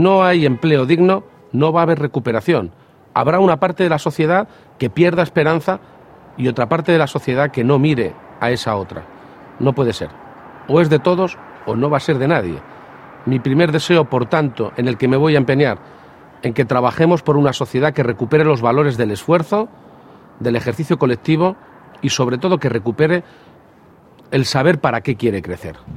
Presidente García-Page: Audio 2 Mensaje Navidad